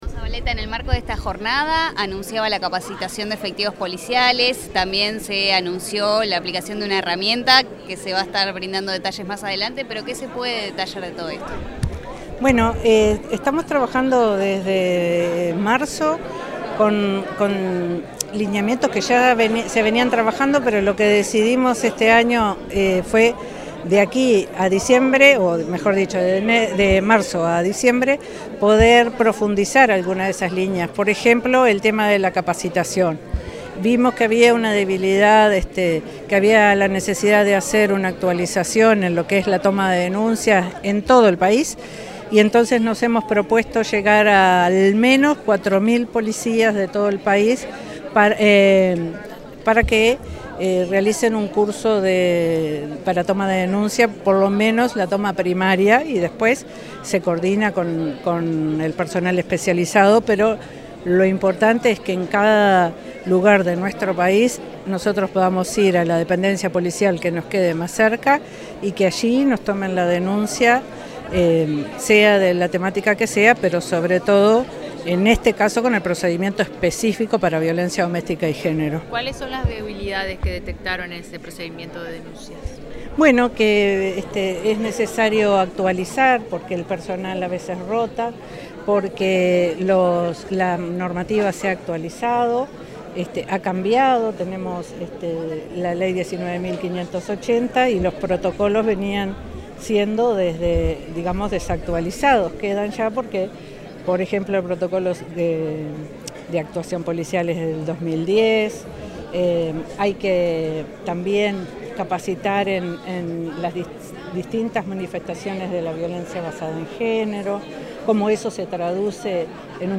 Declaraciones de la encargada de la Dirección Nacional de Políticas de Género, July Zabaleta
Tras participar en la conmemoración del 17. ° aniversario de la institucionalidad de género en el Ministerio del Interior, la encargada de la